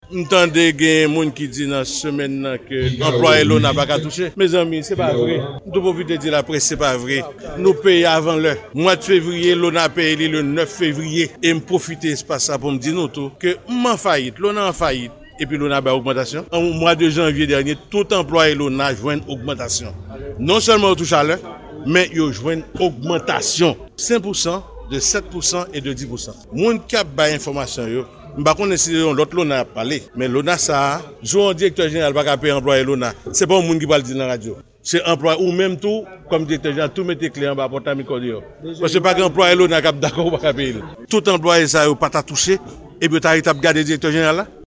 Deklarasyon Direktè ONA a, Chesnel Pierre fè nan laprès